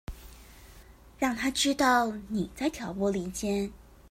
Тайваньский 430